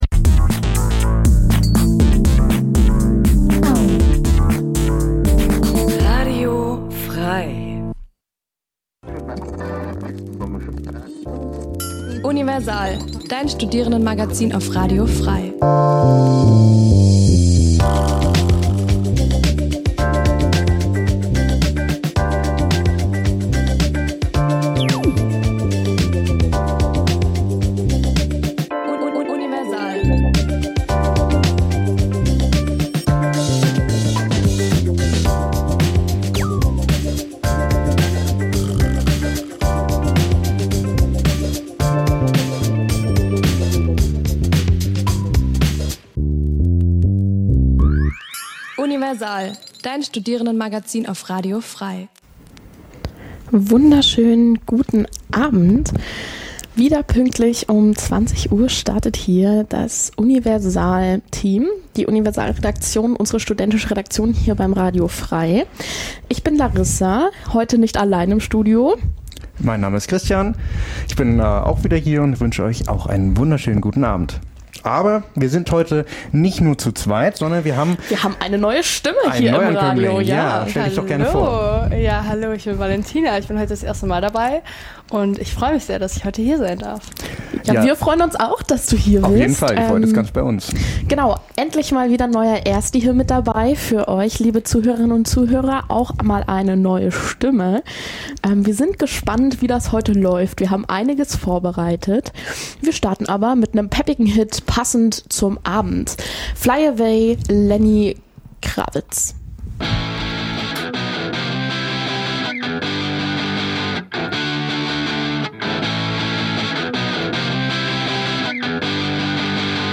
Die Sendungen werden gemeinsam vorbereitet - die Beitr�ge werden live im Studio pr�sentiert.
Studentisches Magazin Dein Browser kann kein HTML5-Audio.